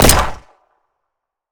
gun_pistol_shot_04.wav